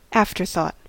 Ääntäminen
Ääntäminen US US : IPA : /ˈæft.ɚ.θɔt/ Haettu sana löytyi näillä lähdekielillä: englanti Käännöksiä ei löytynyt valitulle kohdekielelle.